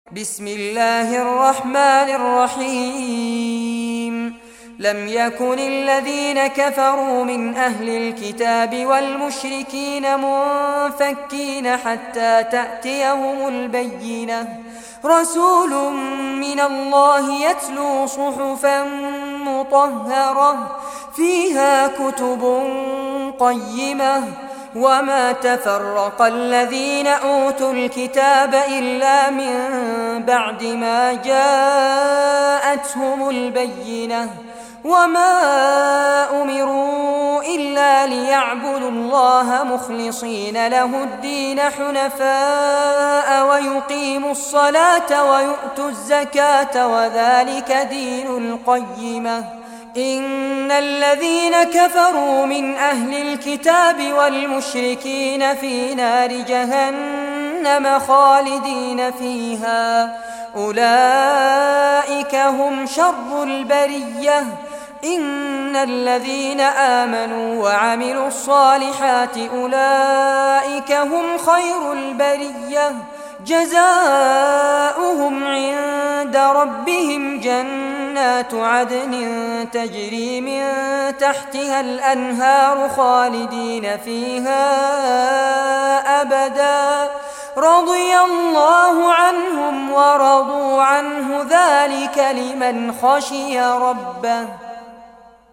Surah Al-Bayyinah Recitation by Fares Abbad
Surah Al-Bayyinah, listen or play online mp3 tilawat / recitation in Arabic in the beautiful voice of Sheikh Fares Abbad.
98-surah-bayyinah.mp3